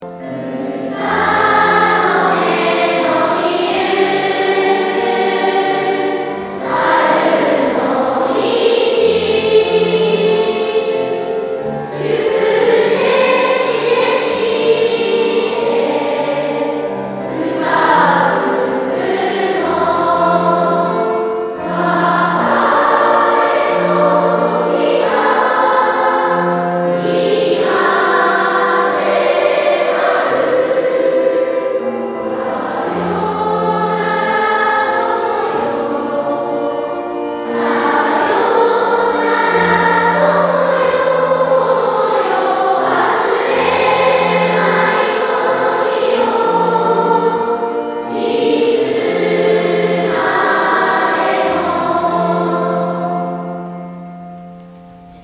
卒業式予行を行いました。